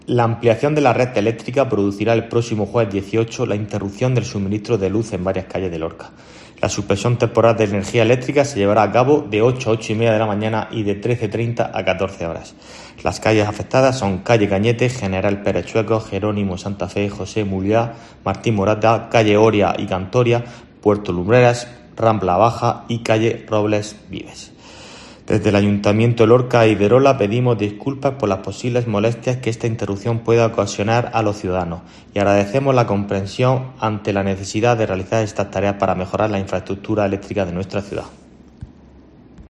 Antonio David Sánchez, concejal Ayto Lorca